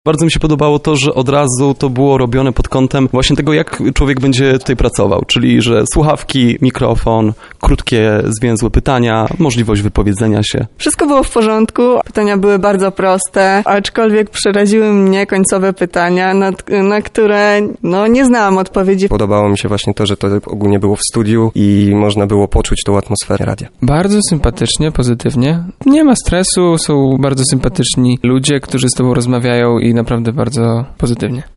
Posłuchajcie, jakie wrażenia mają Ci, którzy przyszli na poprzedni nabór.